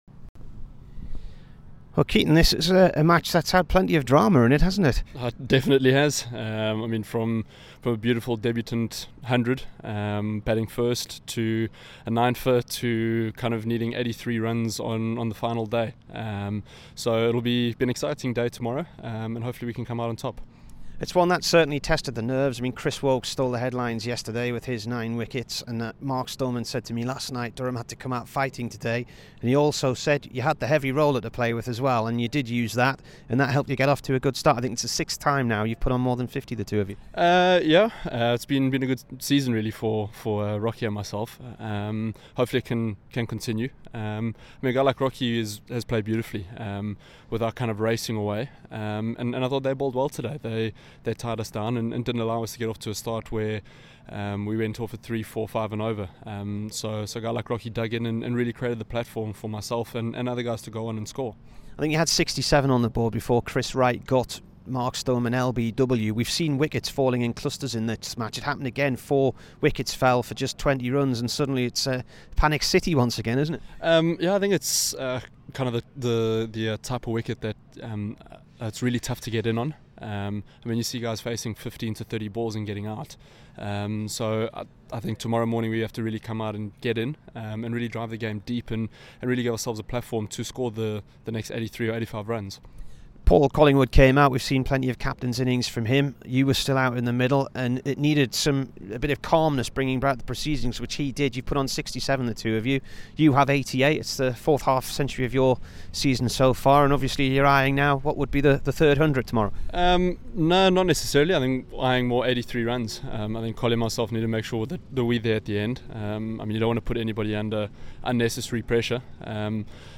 KEATON JENNINGS INT